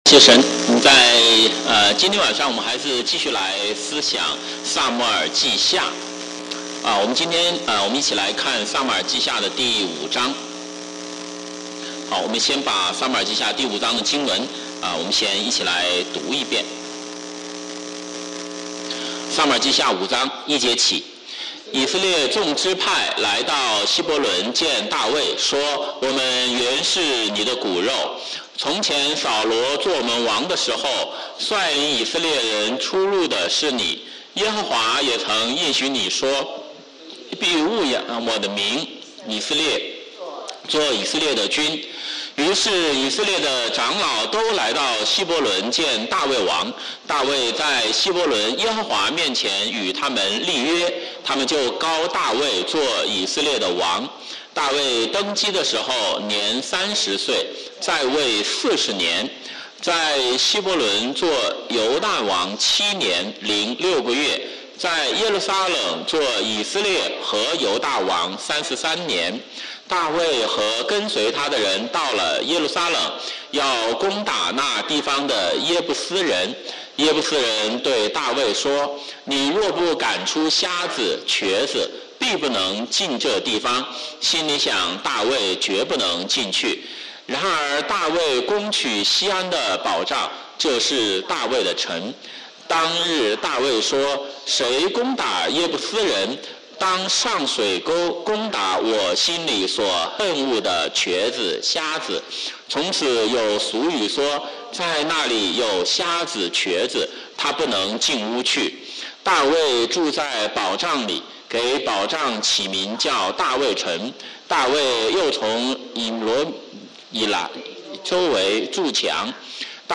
週五晚上查經講道錄音